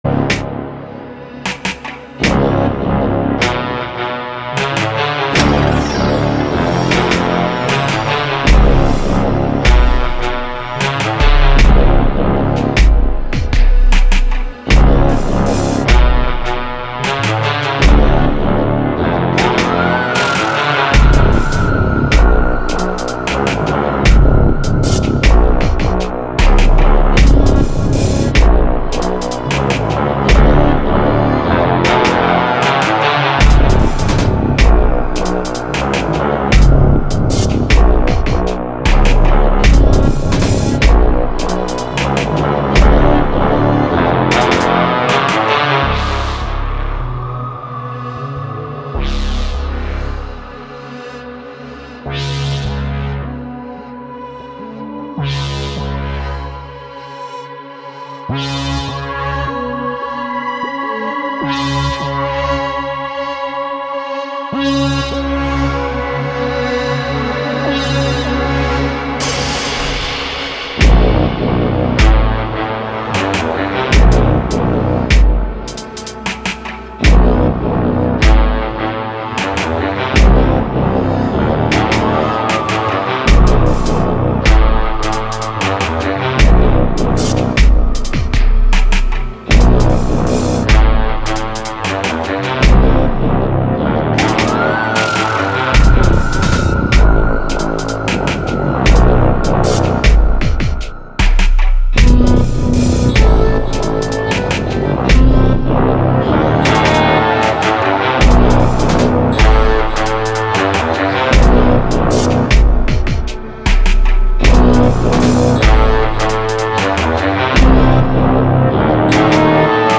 through an awry and granular sound